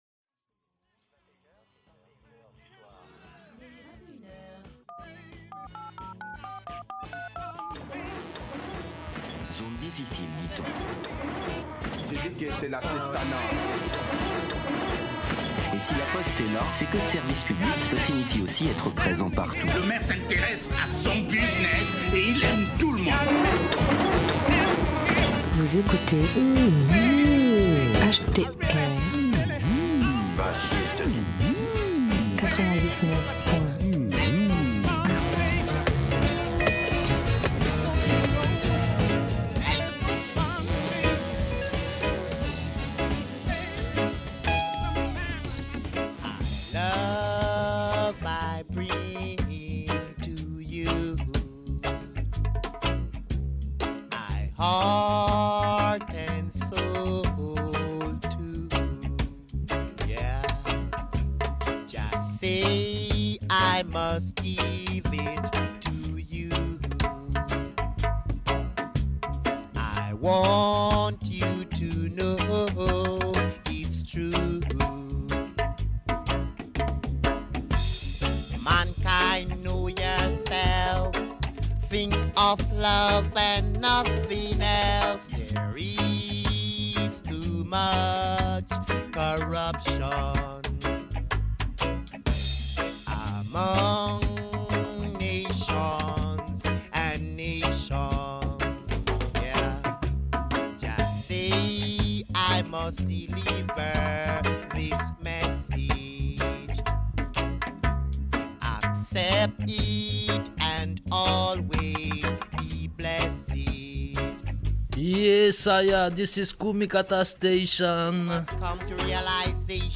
Roots Dub 'n Kultcha !!
strictly vinyl